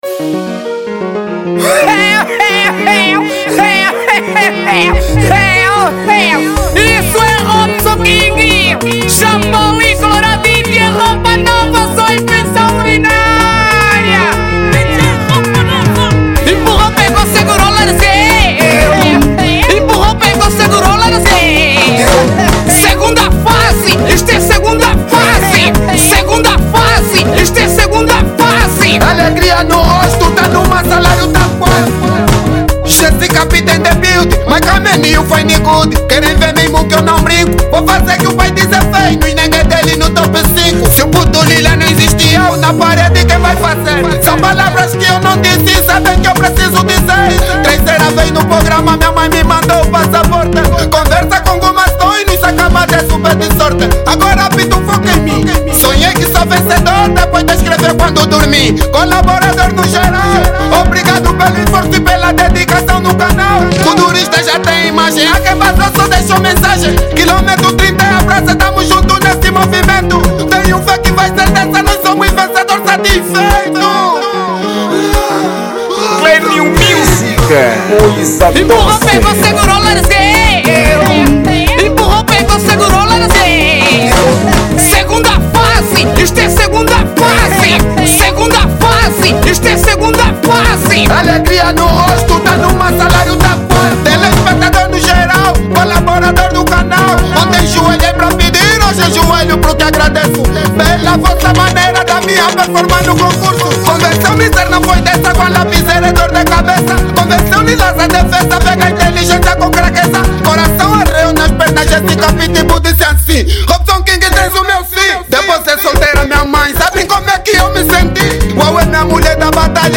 Género: Kuduro